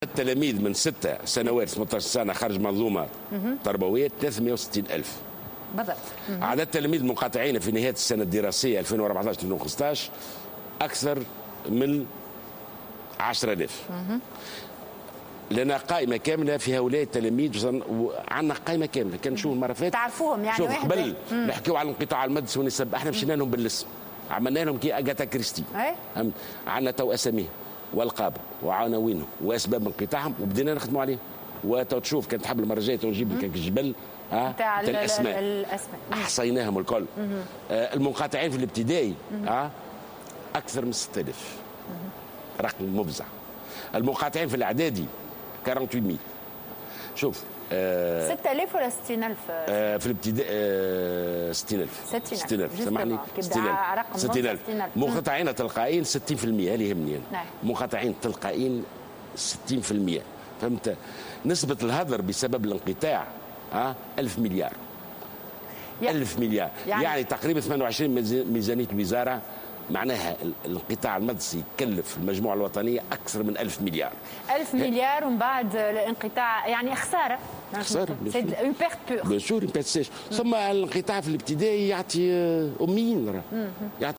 وصف وزير التربية، ناجي جلول في مداخلة له على قناة الحوار التونسي اليوم، الخميس الأرقام المتعلقة بالانقطاع المدرسي في تونس بالمفزعة.